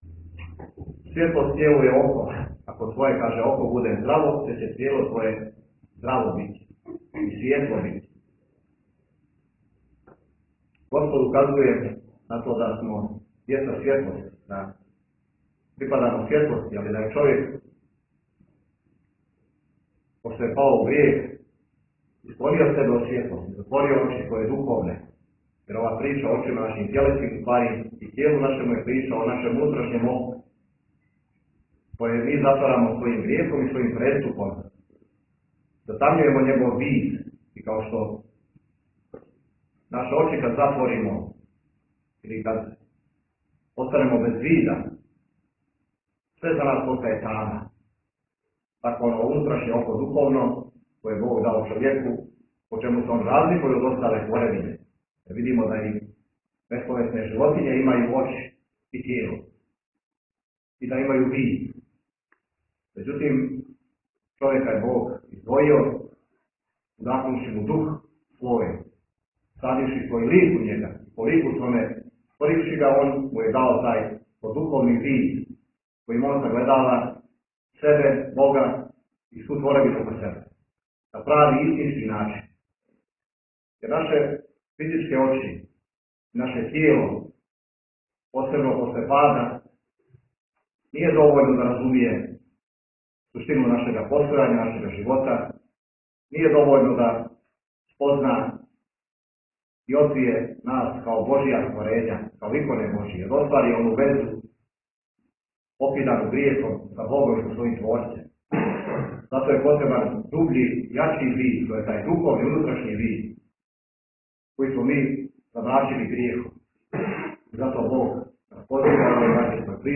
Бесједа
У недељу 7. јула 2019. године, на празник Рођења Светог Јована Крститеља, служена је литургија у храму Свете Текле у Даниловграду.